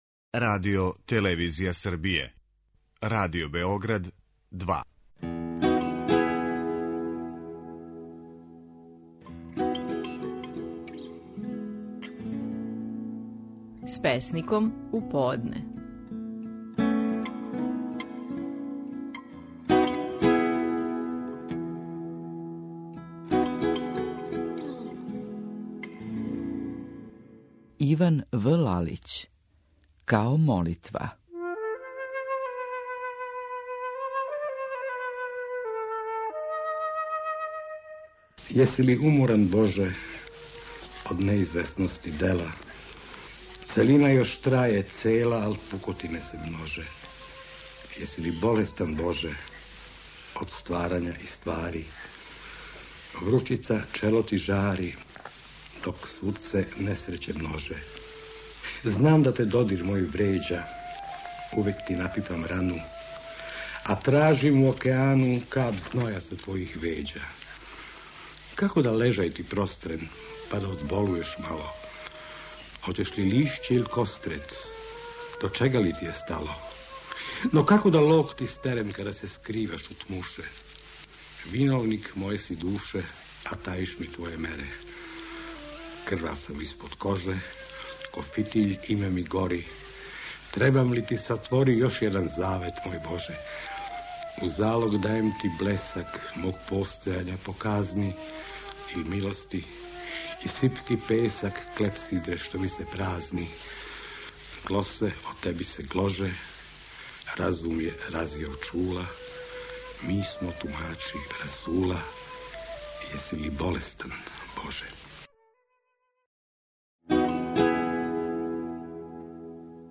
Стихови наших најпознатијих песника, у интерпретацији аутора.
„Као молитва" - назив је песме коју казује Иван В. Лалић.